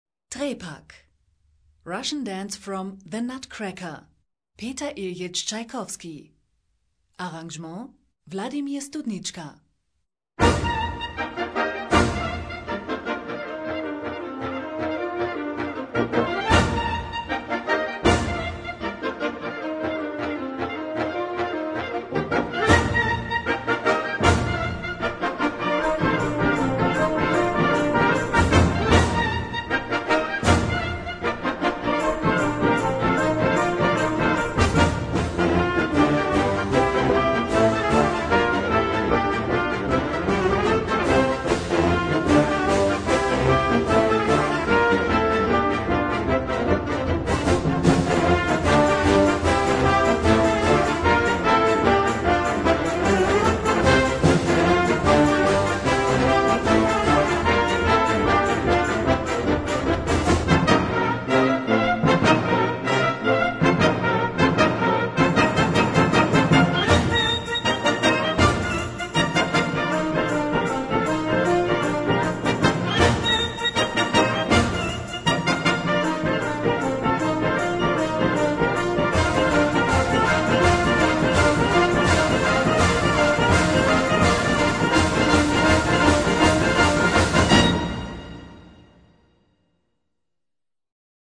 Gattung: Russischer Tanz
Besetzung: Blasorchester